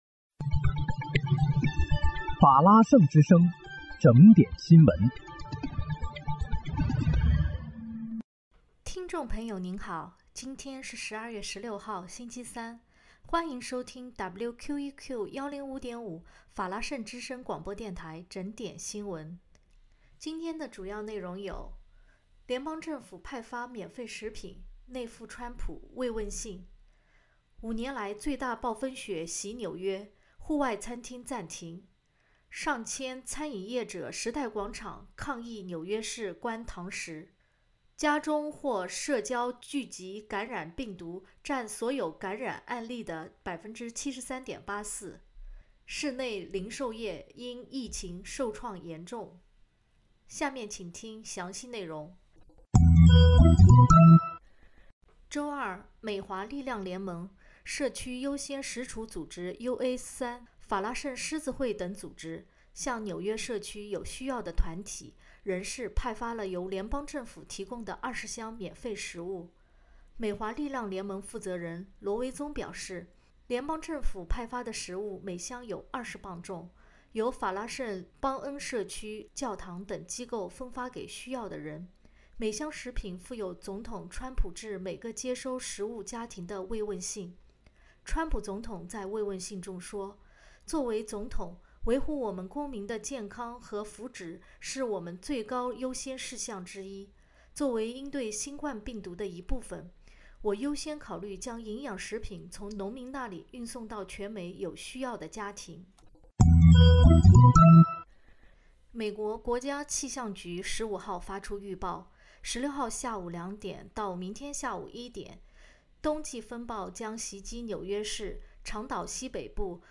12月16日（星期三）纽约整点新闻
欢迎收听WQEQ105.5法拉盛之声广播电台整点新闻。